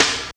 46.08 SNR.wav